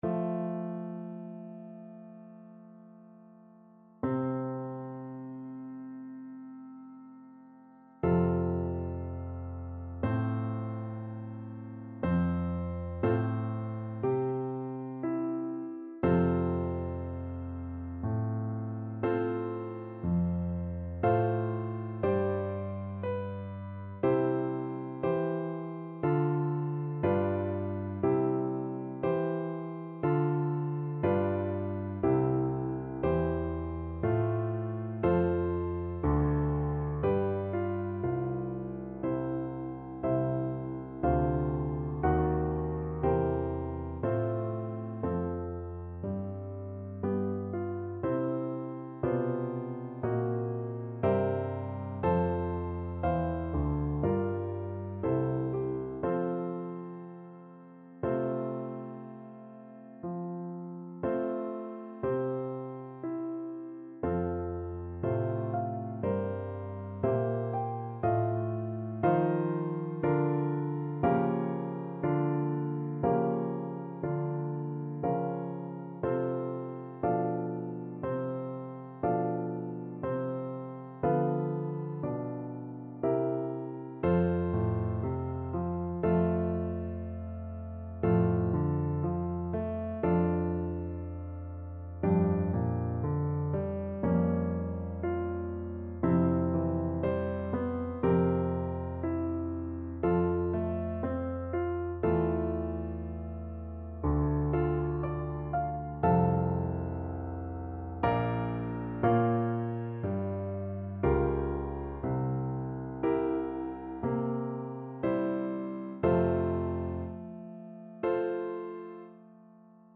2/4 (View more 2/4 Music)
~ = 100 Adagio =c.60